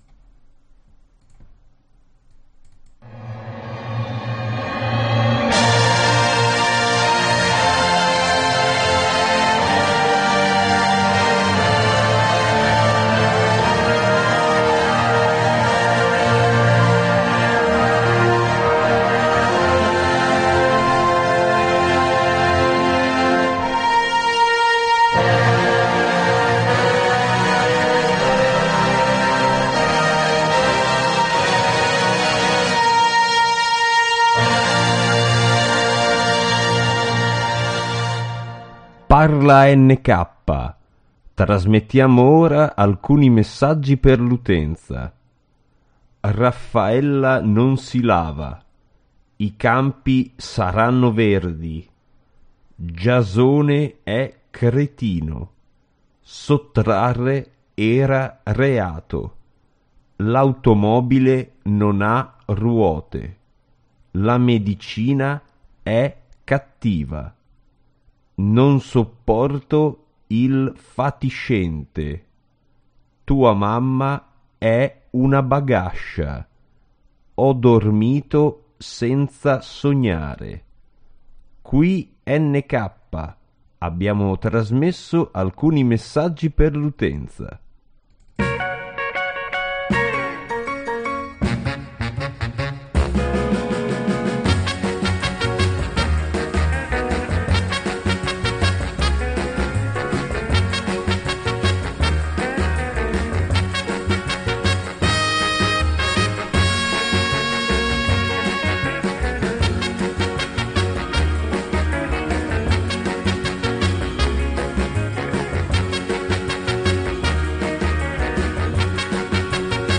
via Skype.